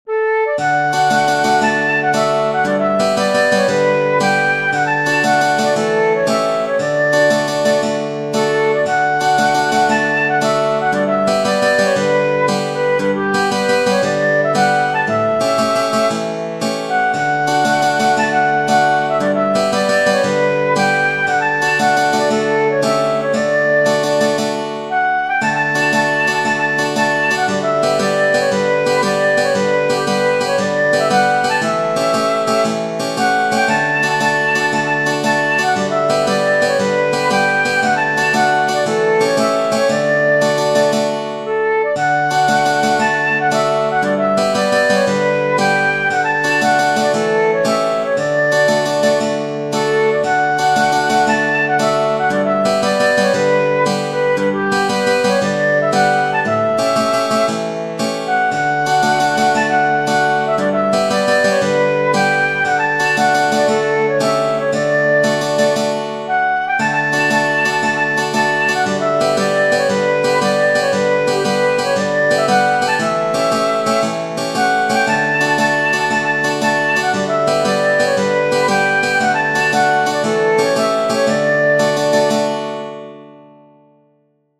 Tradizionale Genere: Folk "The Road to the Isles" è una famosa canzone scritta dal poeta celtico Kenneth Mcleod e pubblicata nel 1917.